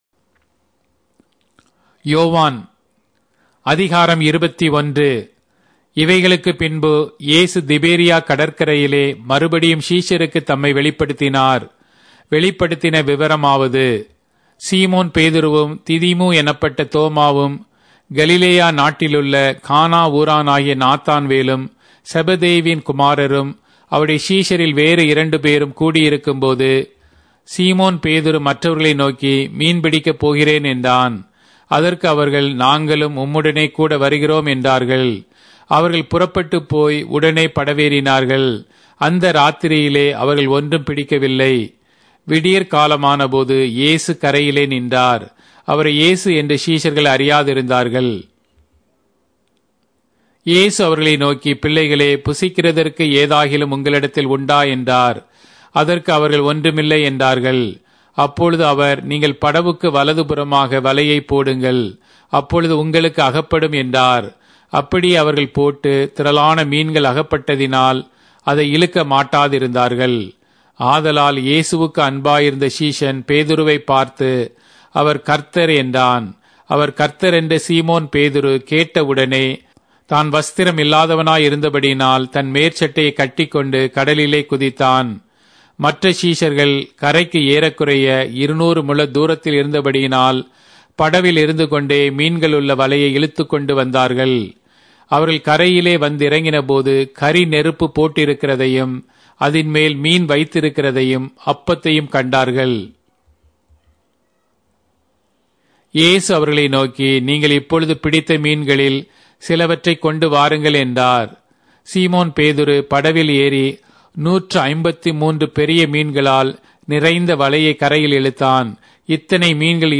Tamil Audio Bible - John 4 in Hov bible version